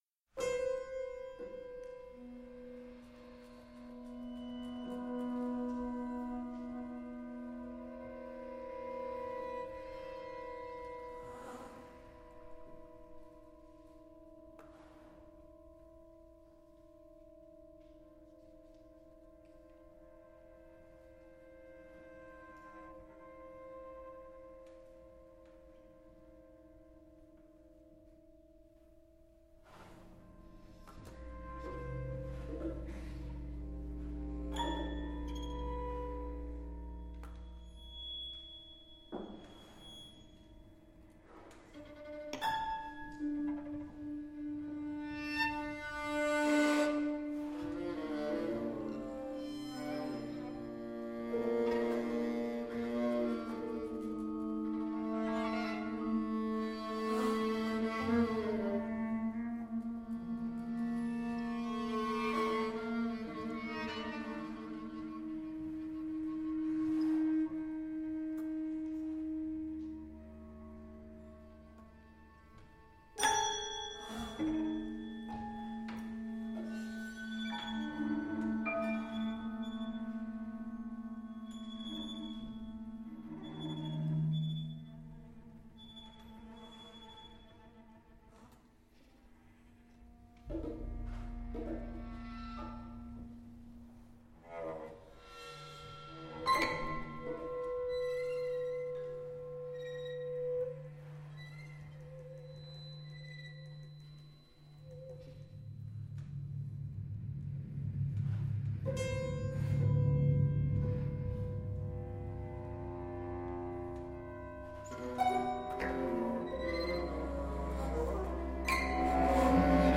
For ensemble with amplified viola d’amore (ca.10′) (2010)